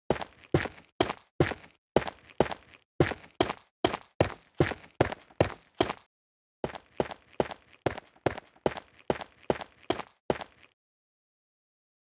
test_breaking_100_50.ogg
Doesn't seem to be completely in a linear scale, but it definitely feels louder than it should when reducing the volume to 50%.